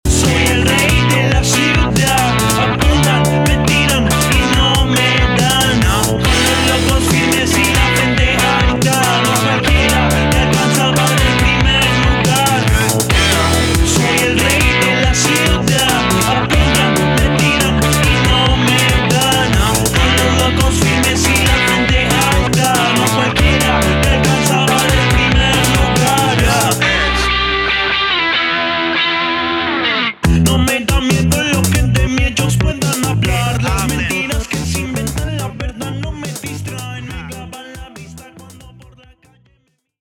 Género: Rap / Latin Rap.